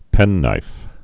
(pĕnnīf)